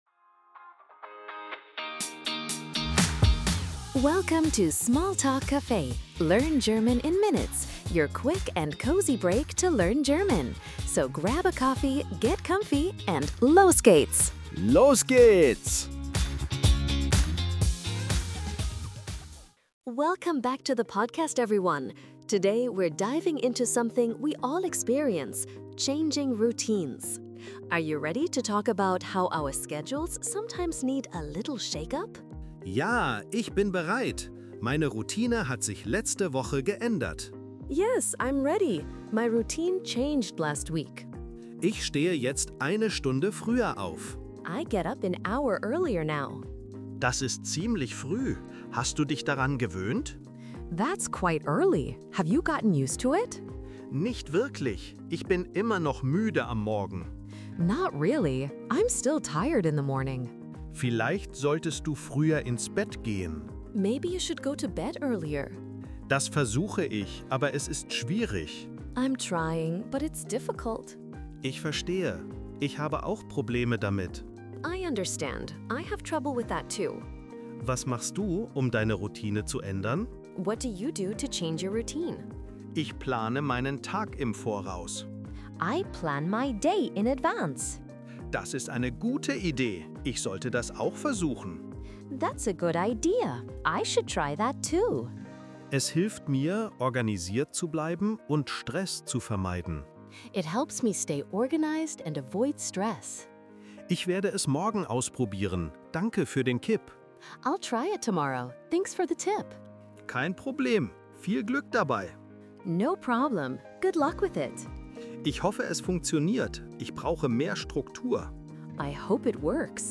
Tune in for quick, real-life dialogues, helpful tips, and the confidence boost you ne…